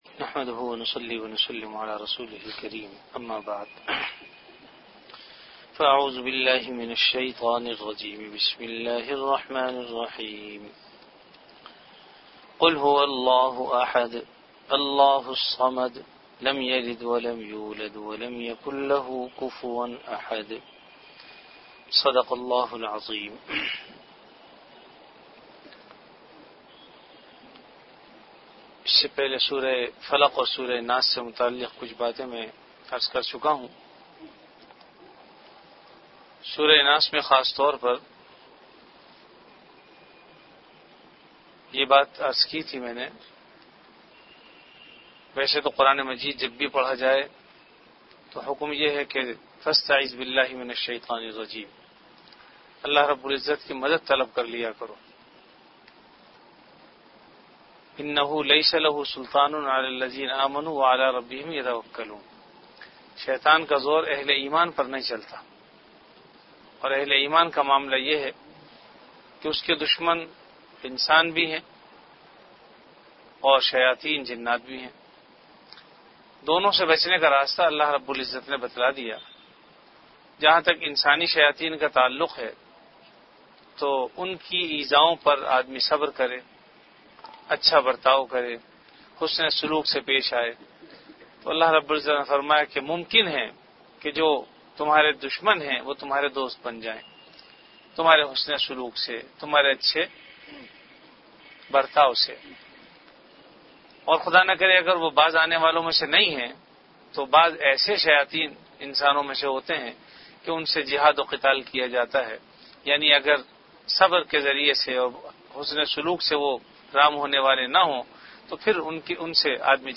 Delivered at Jamia Masjid Bait-ul-Mukkaram, Karachi.
Dars-e-quran · Jamia Masjid Bait-ul-Mukkaram, Karachi
Event / Time After Isha Prayer